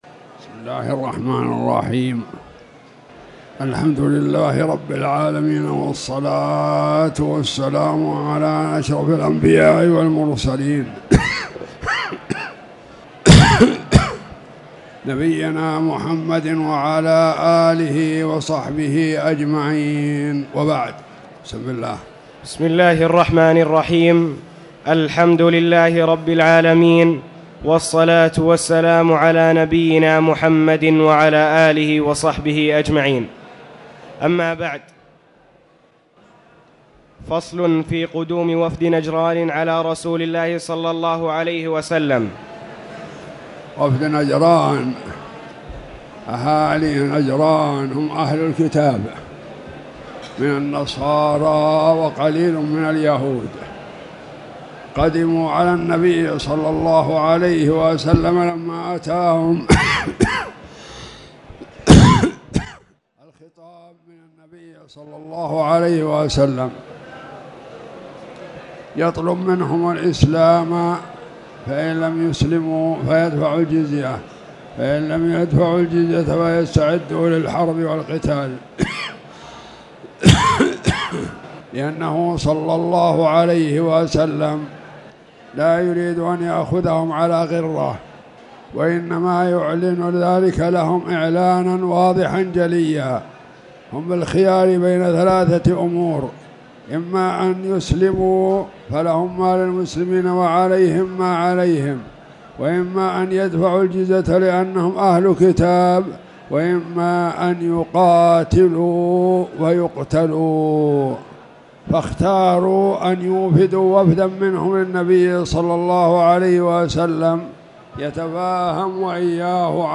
تاريخ النشر ٣ رجب ١٤٣٨ هـ المكان: المسجد الحرام الشيخ